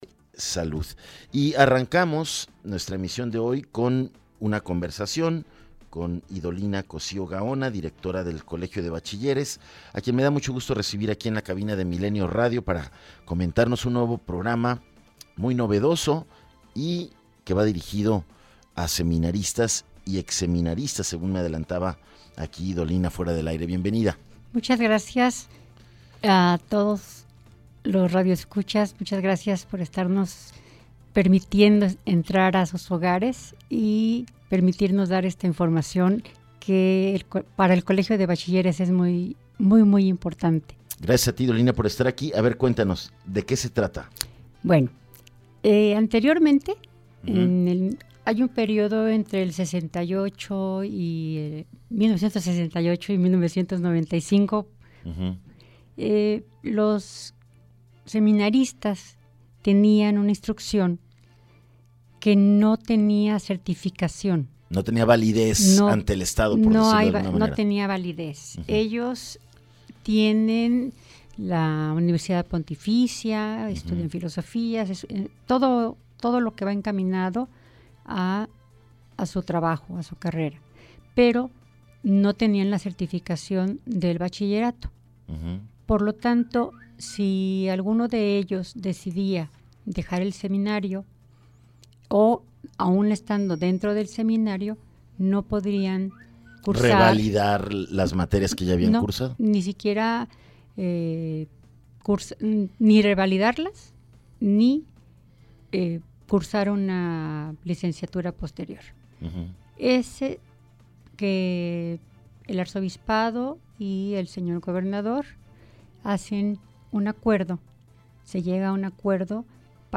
ENTREVISTA 100316